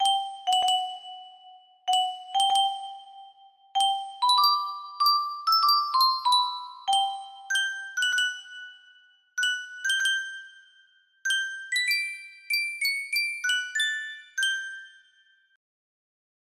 Yunsheng Music Box - Beethoven Septet in E Flat Major 3760 music box melody
Full range 60